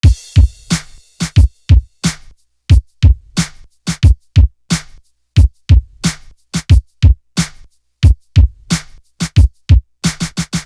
Movie Drum.wav